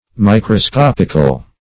Microscopic \Mi`cro*scop"ic\, Microscopical \Mi`cro*scop"ic*al\,